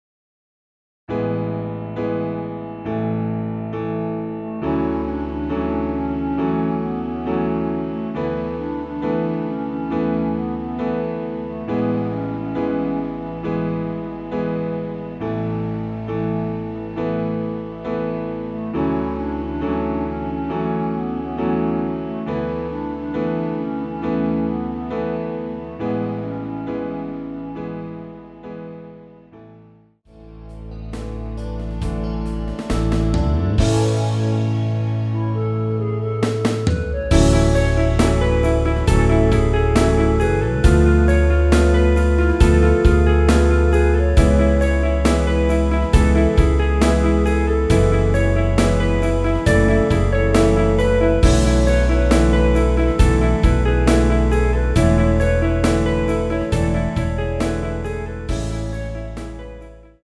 전주 없이 시작하는곡이라 전주 1마디 만들어놓았습니다.(미리듣기 참조)
원키에서(-3)내린 멜로디 포함된 MR입니다.
앞부분30초, 뒷부분30초씩 편집해서 올려 드리고 있습니다.
중간에 음이 끈어지고 다시 나오는 이유는